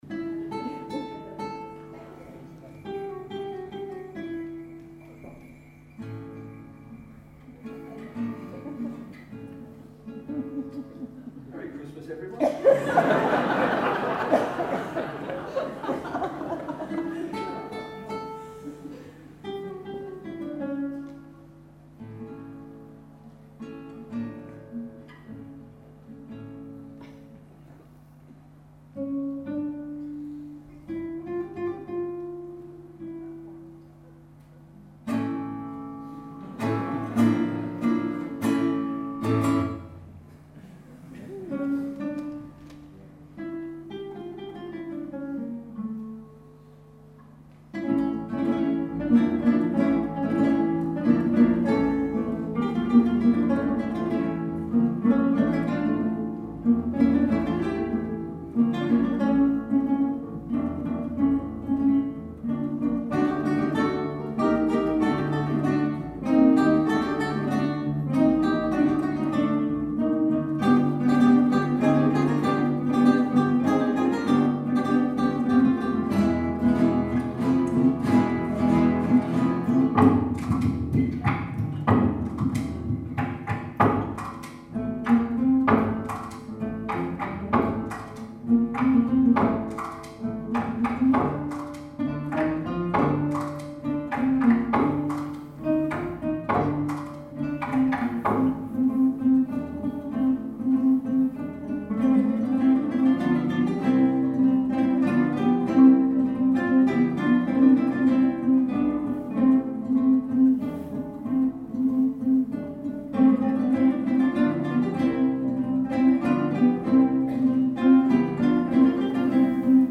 Hampshire Guitar Orchestra - our living history - 2014
March 31st - was it a Mobile Phone, a Morris Dancer, or Santa Claus in our recent concert?
hago in Basingstoke